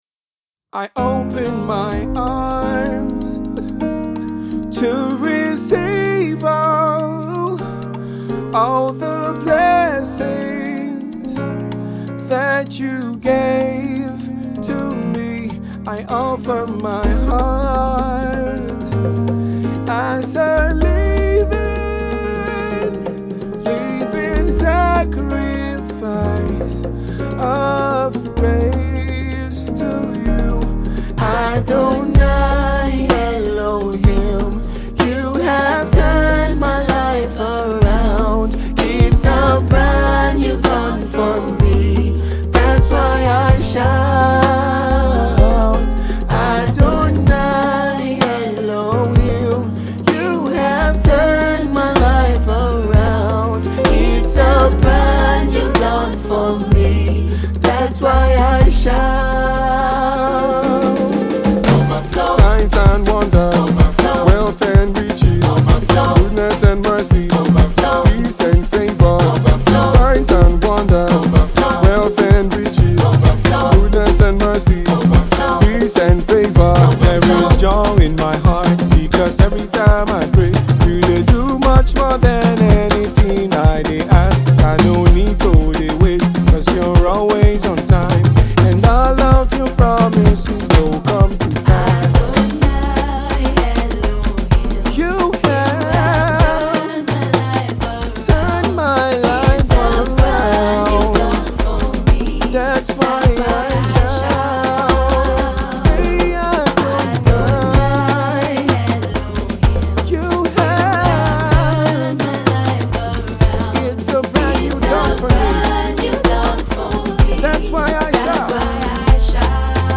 and talented Gospel music artiste.